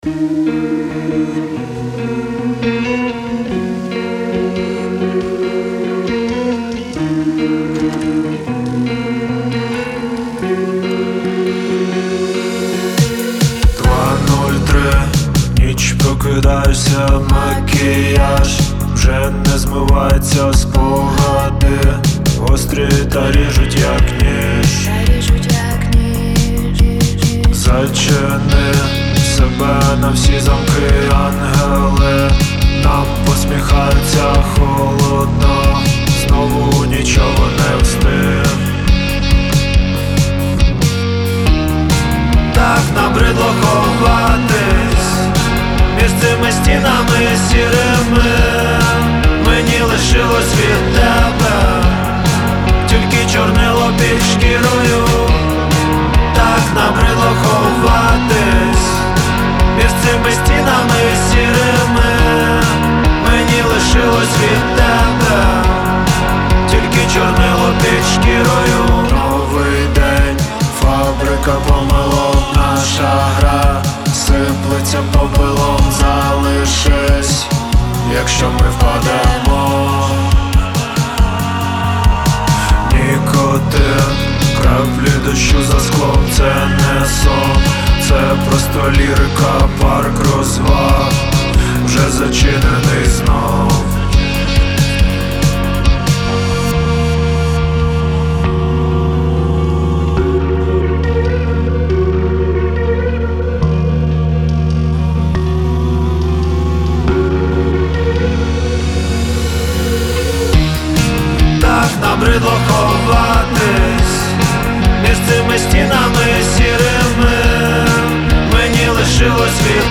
• Жанр: Rock